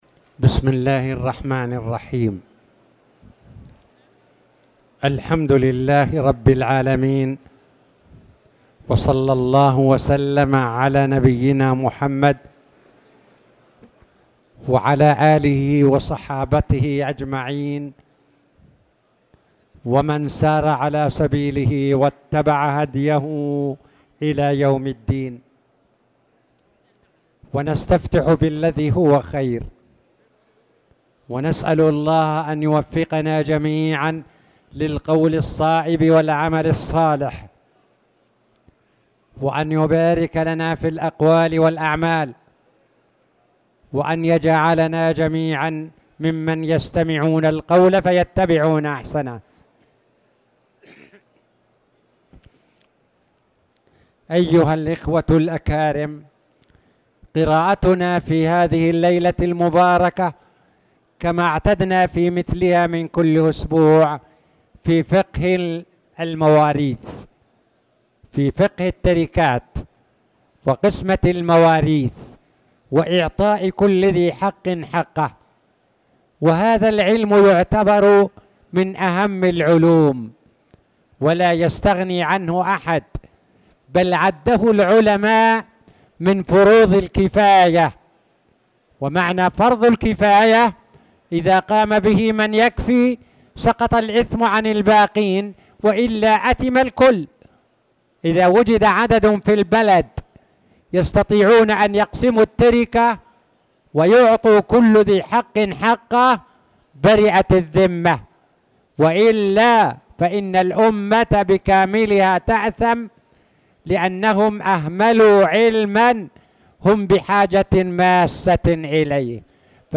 تاريخ النشر ٢٣ محرم ١٤٣٨ هـ المكان: المسجد الحرام الشيخ